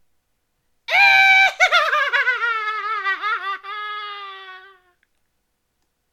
evil laugh-01
evil ForScience frightening horror insane laugh male psychotic sound effect free sound royalty free Funny